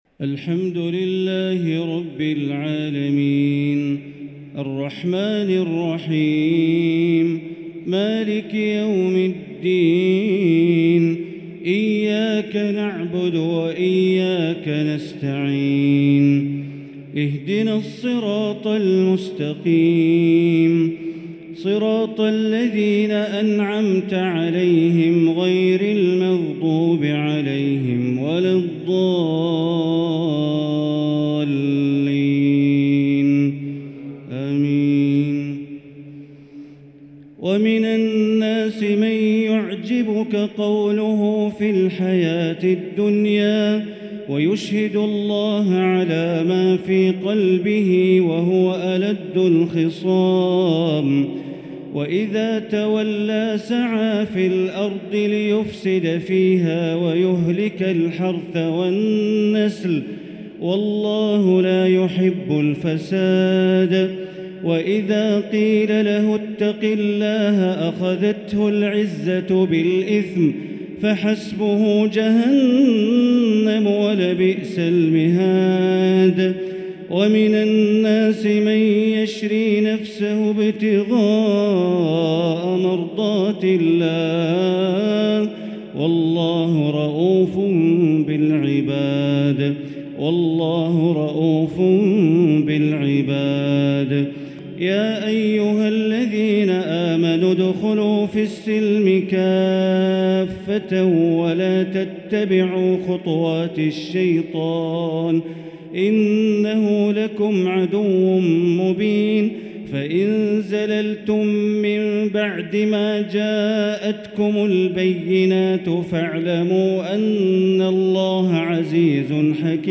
تراويح ليلة 3 رمضان 1444هـ من سورة البقرة (204-248) | Taraweeh 3 st night Ramadan 1444H > تراويح الحرم المكي عام 1444 🕋 > التراويح - تلاوات الحرمين